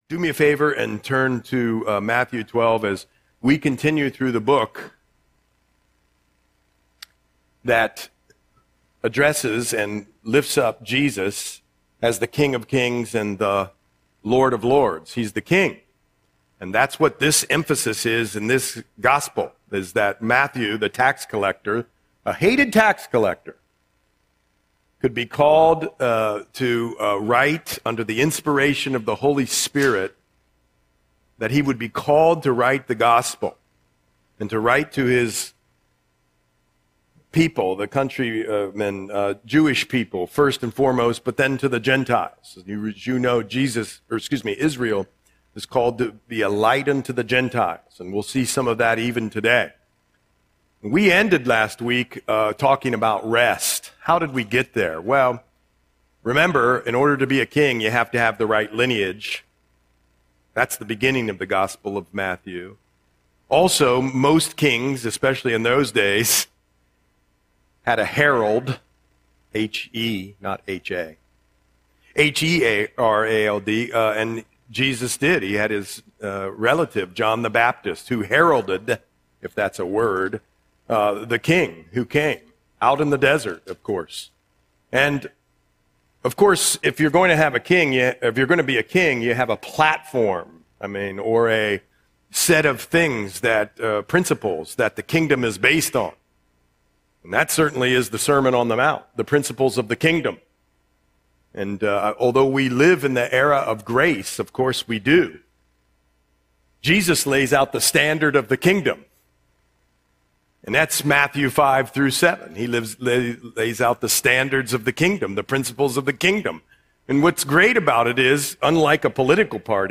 Audio Sermon - February 22, 2026